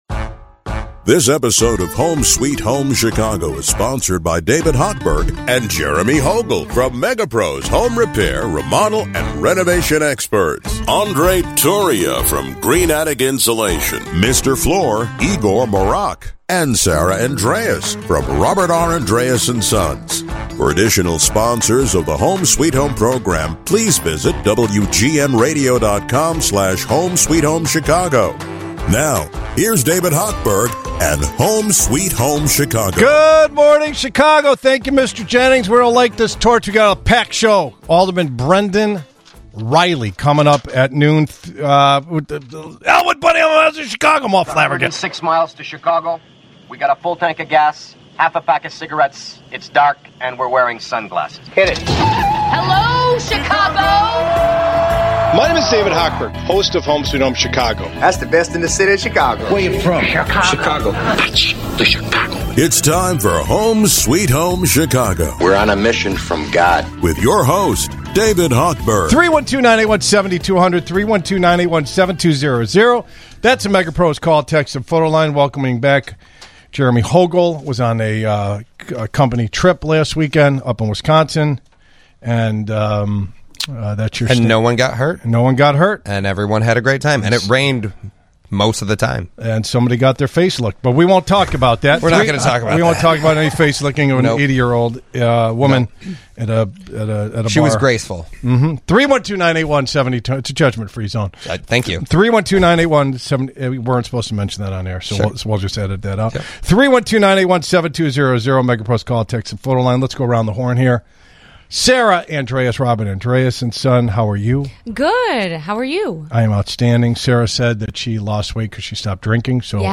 Throughout the show, listeners call and text to ask their questions and get the information they need for the best outcome!